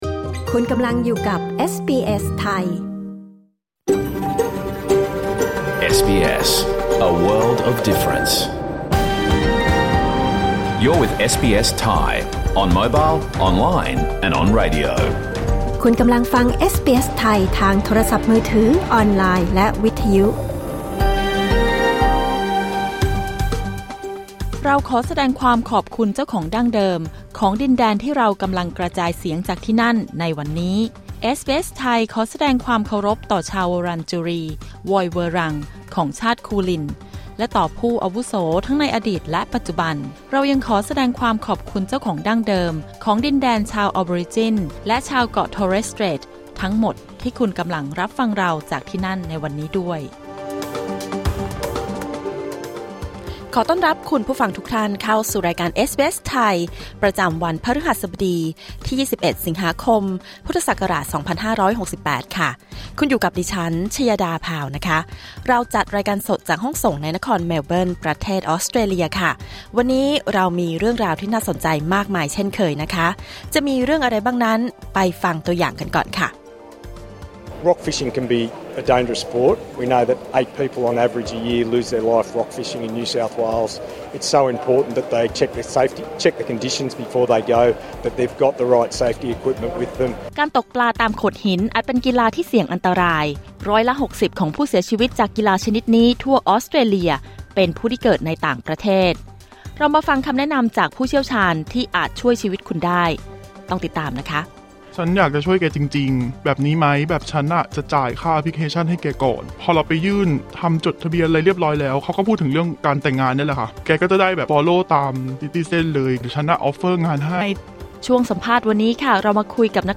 รายการสด 21 สิงหาคม 2568